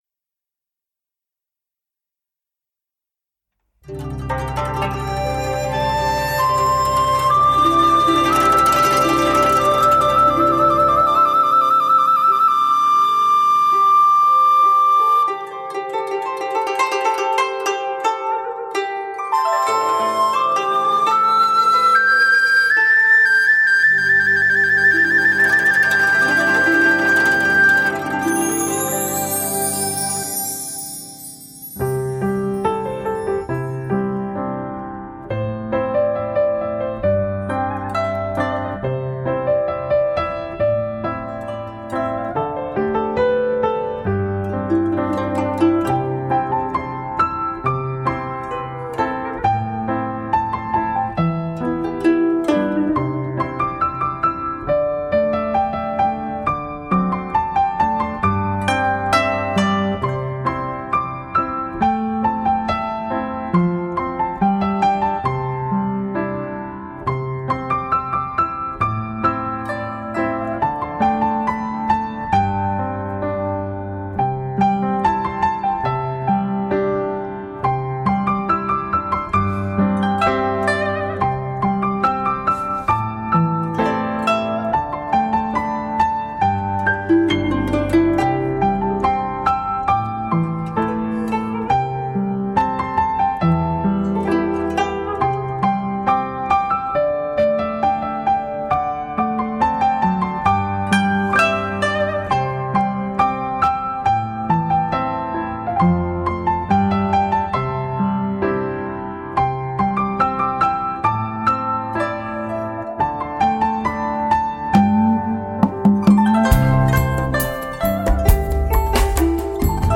令人折服浪漫气质 此情不渝钢琴恋曲
流连在黑白琴键之间 品味完美无暇的音色